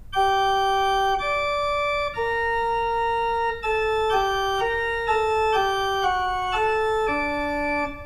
g-fugue.wav